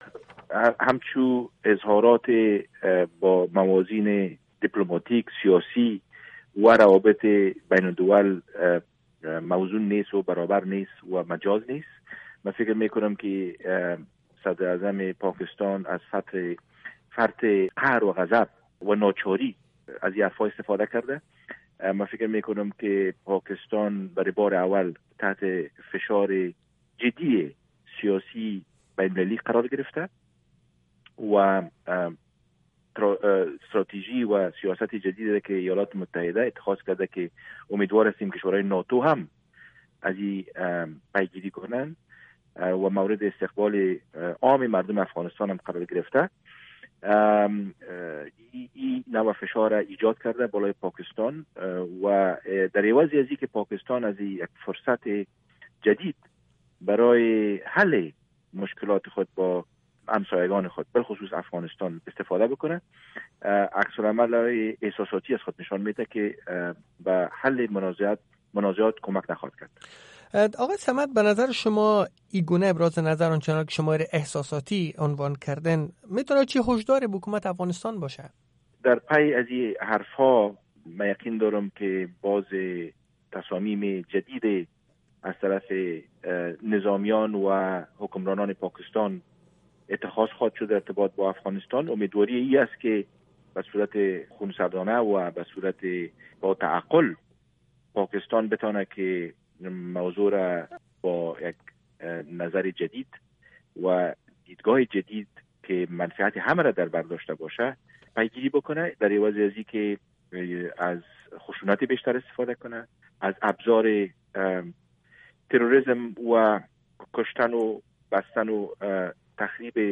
مصاحبۀ کامل آقای عمر صمد، دپلومات پیشن افغانستان، را در اینجا بشنوید